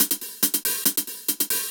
Index of /musicradar/ultimate-hihat-samples/140bpm
UHH_AcoustiHatA_140-01.wav